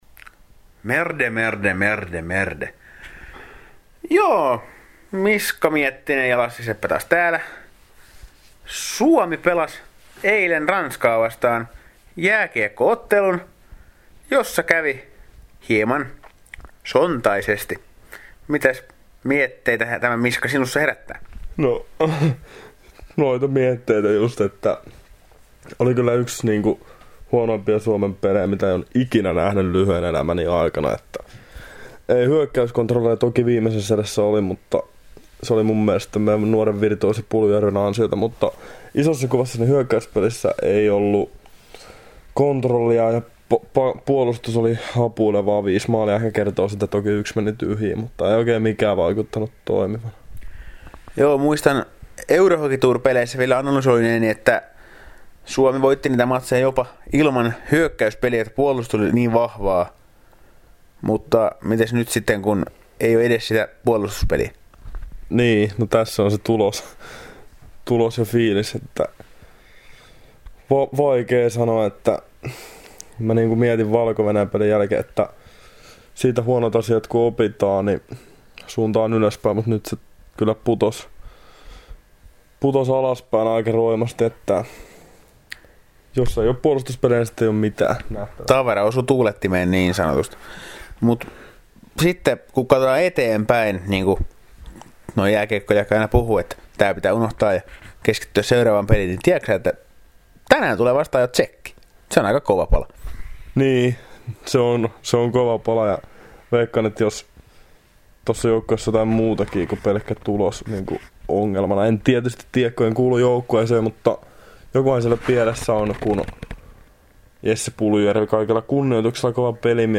Pariisissa paikan päällä olevat toimittajat avaavat lähtökohtia Tšekki-otteluun.